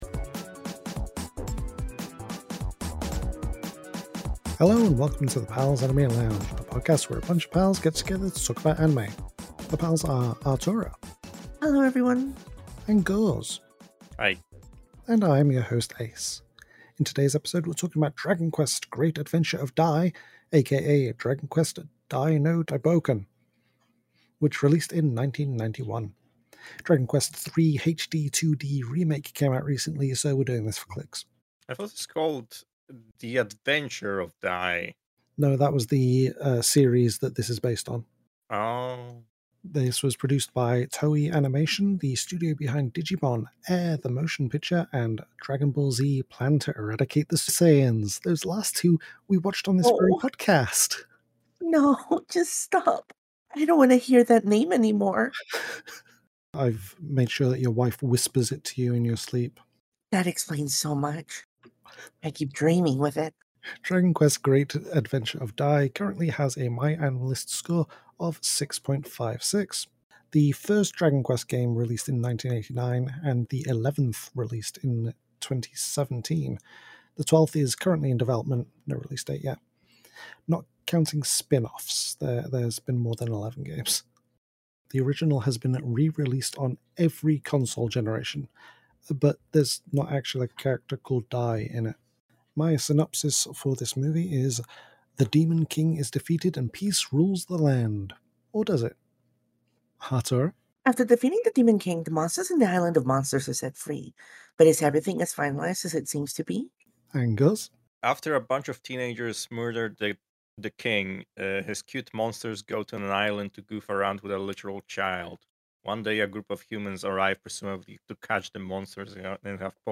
Welcome to the Pals Anime Lounge, a podcast where a bunch of pals get together to talk about anime!